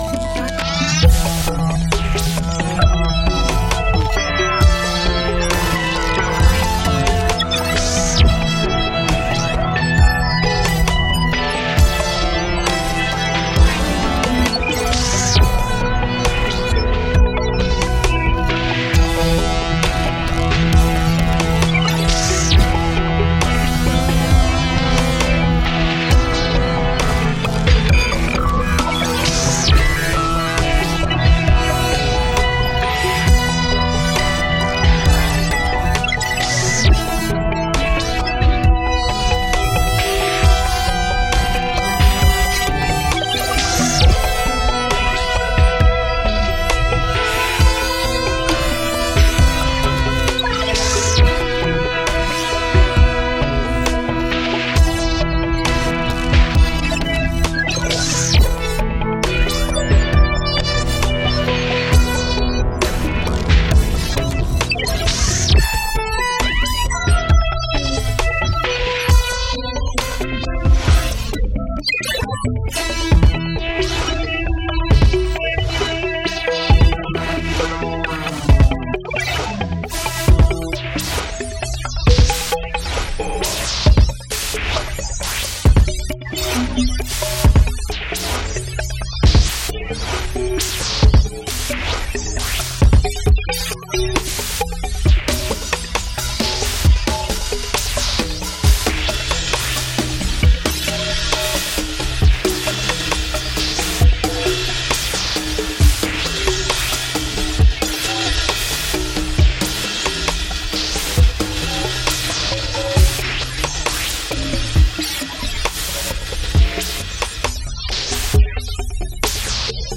psypunk